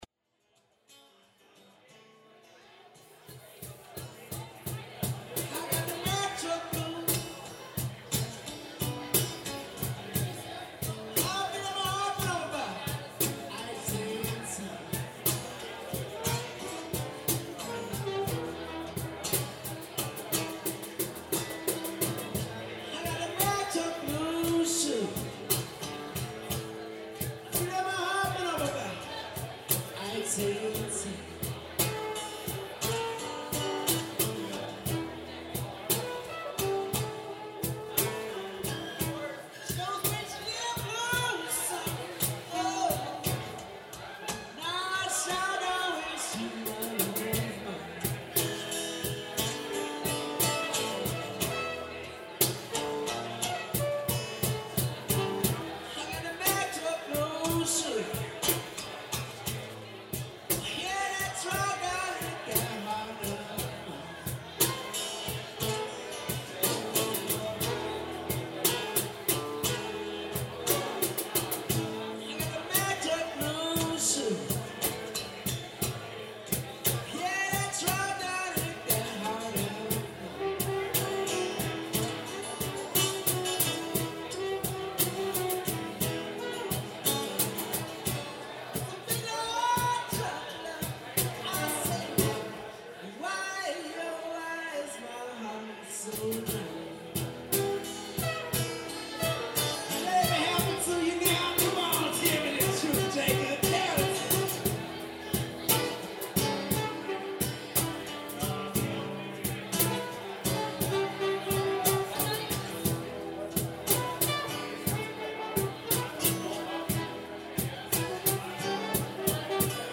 Gunnison, CO